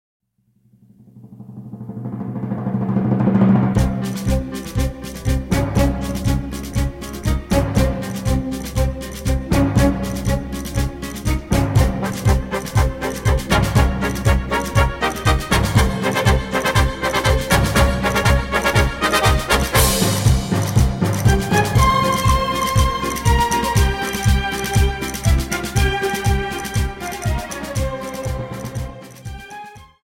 Paso Doble 60 Song